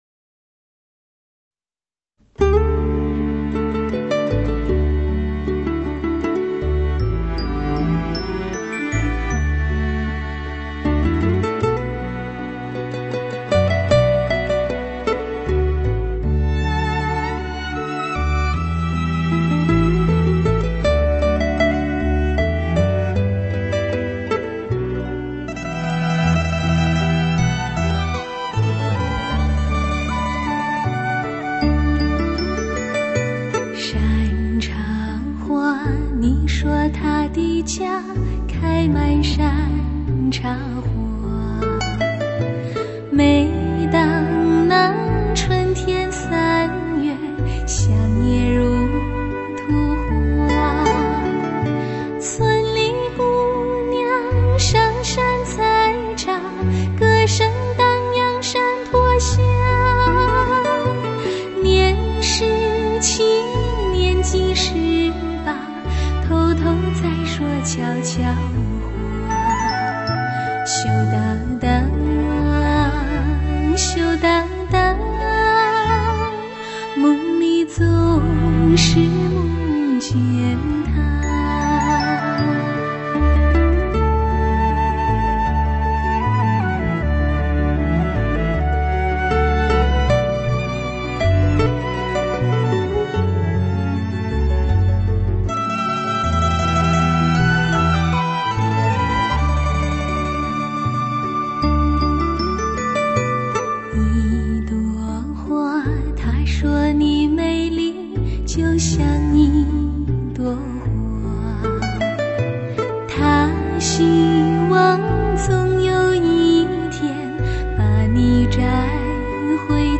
独特的演唱风格
动用AKG顶班电子管麦克风C12VR作录音收录
声音饱满、开阔
老版和XRCD2版相比，老版人声似更为醇厚。